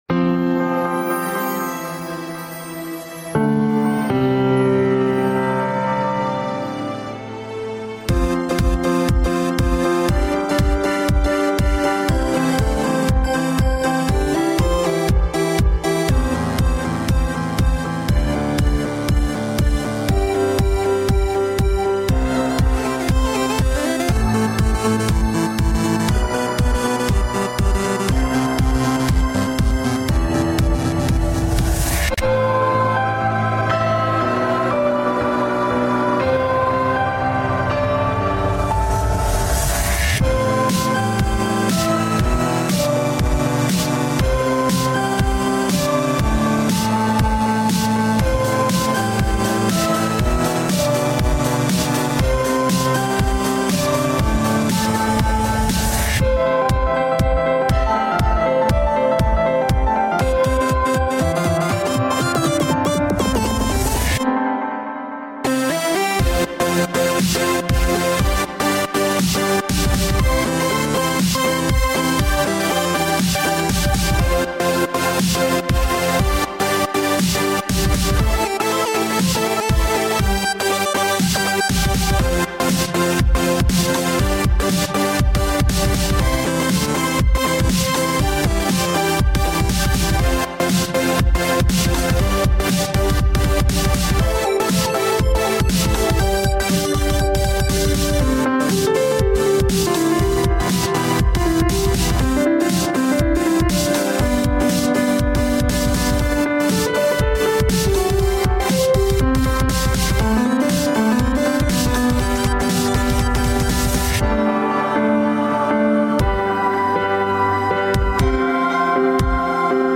BPM: 120
Genre: Holiday House?